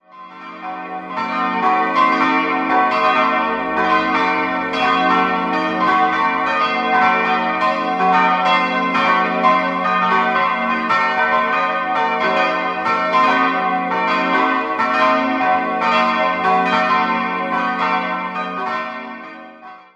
Idealquartett (ungefähr): fis'-a'-h'-d'' Die drei kleineren Glocken wurden im Jahr 1952 gegossen, vermutlich von der Gießerei Hofweber (Regensburg). Die große Glocke ("Wasserglocke") stammt aus dem Jahr 1474.